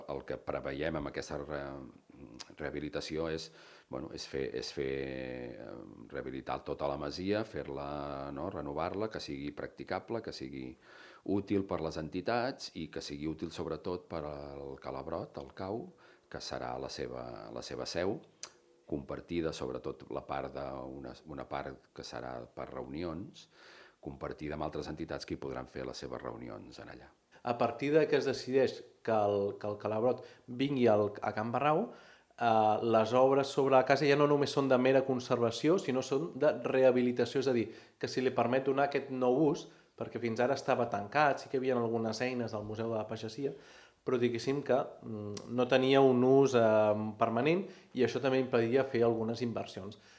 Són el regidor d'Urbanisme, Xavi Collet, i el de Patrimoni, Bernat Calvo: